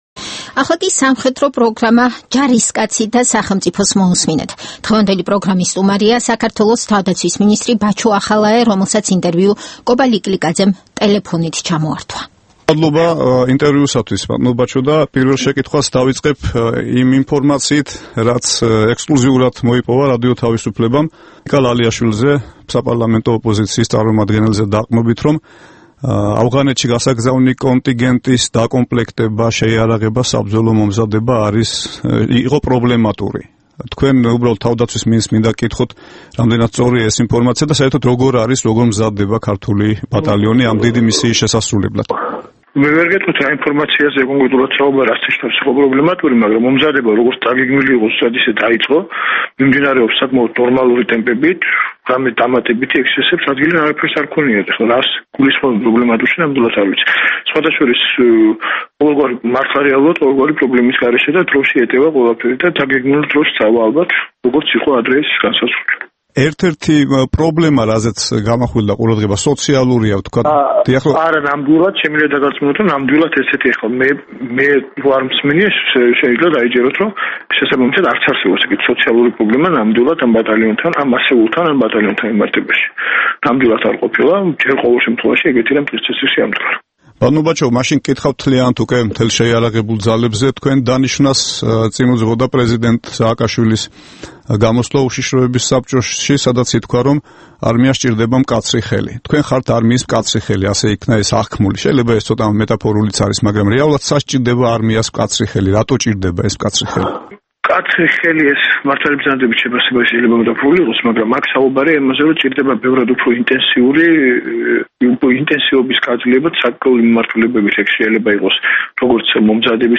ინტერვიუ საქართველოს თავდაცვის მინისტრთან